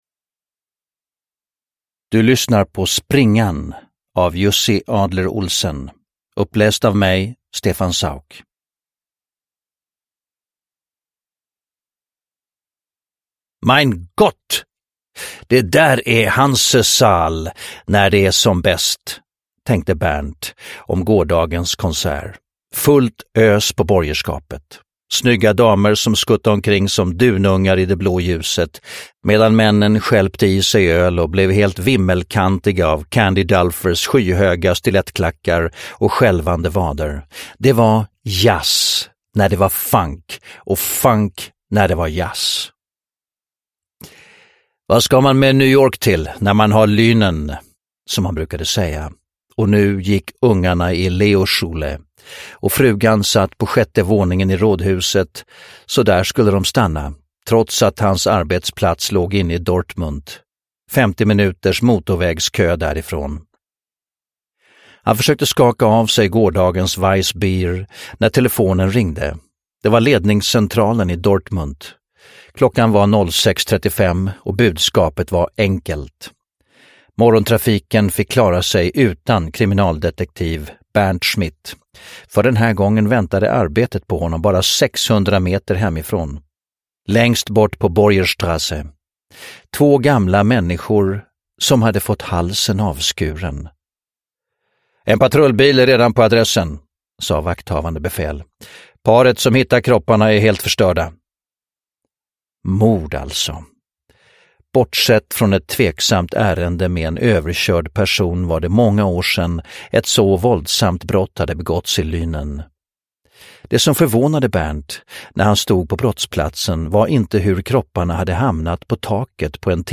Springan – Ljudbok – Laddas ner
Uppläsare: Stefan Sauk